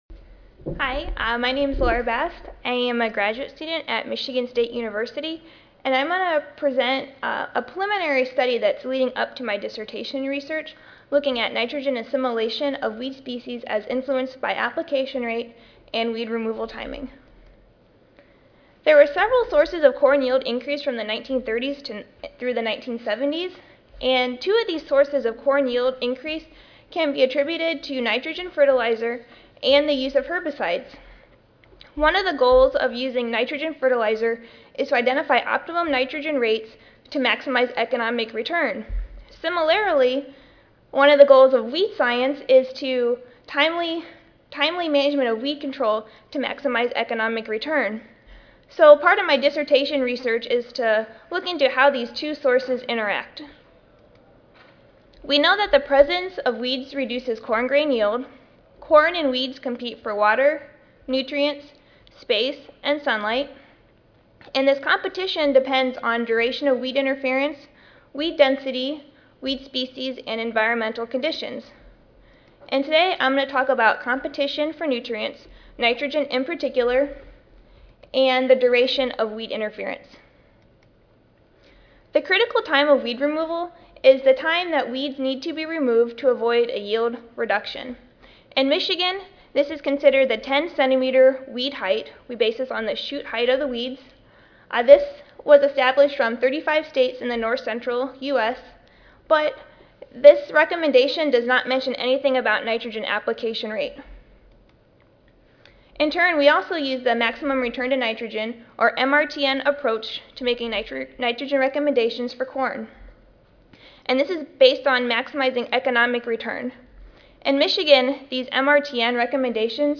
NC Audio File Recorded presentation Timely weed control and adequate nitrogen supply are both necessary to maximize corn grain yield and economic return.